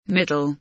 middle kelimesinin anlamı, resimli anlatımı ve sesli okunuşu